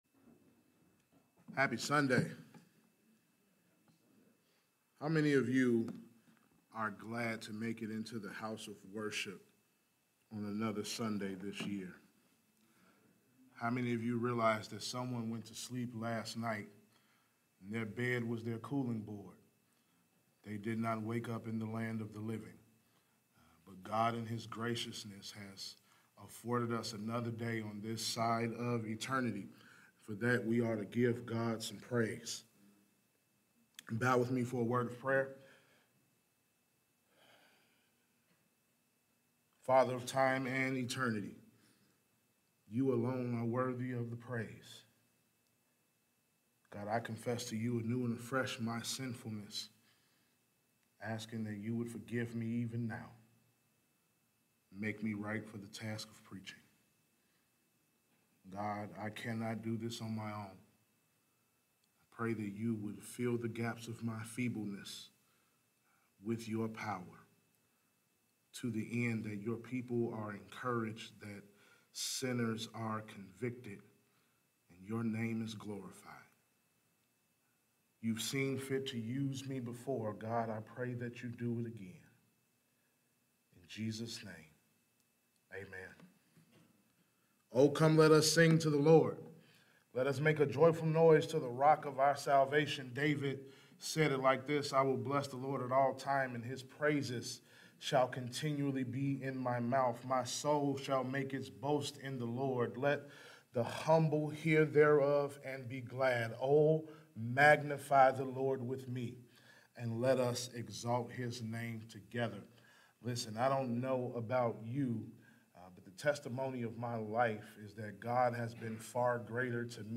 Service Type: Sunday Morning Worship